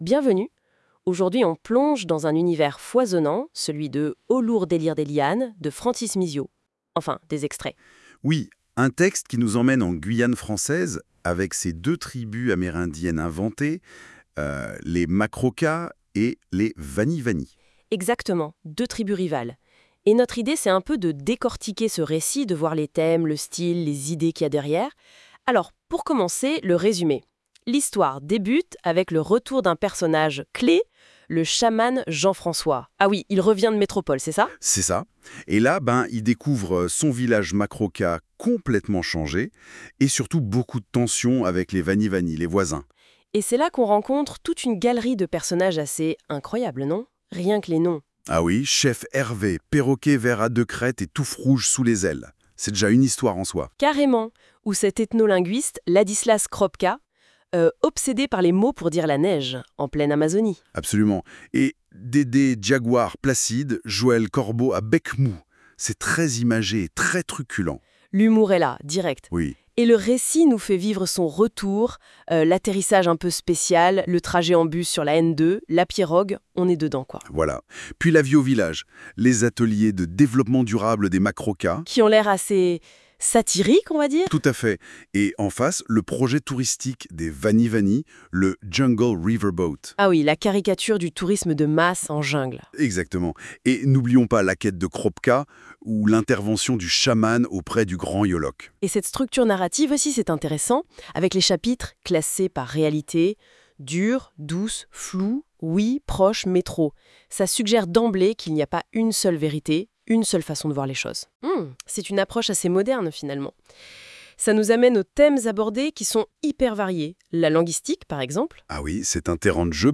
• Ici un podcast de 5 minutes 18 secondes (généré par IA) où deux personnes vous présentent et expliquent le roman (et abordent d’autres sujets contenus dans le roman que ceux évoqués dans la vidéo ci-dessus :